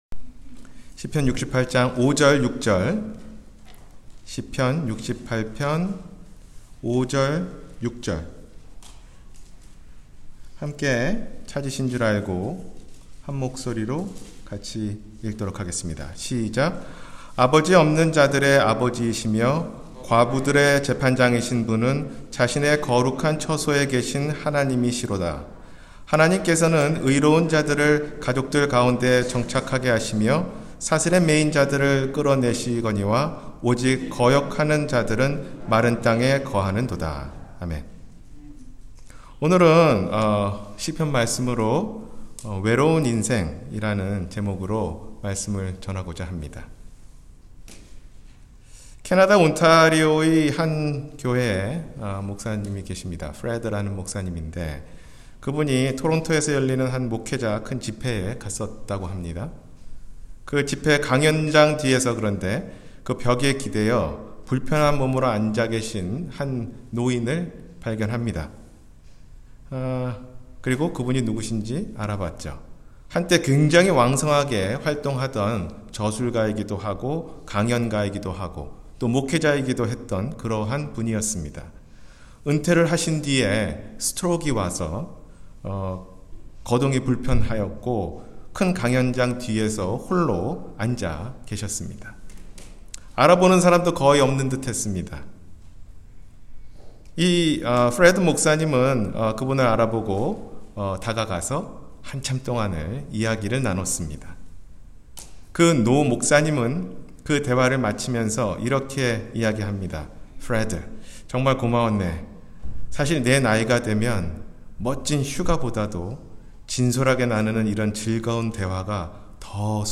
외로운 인생 – 주일설교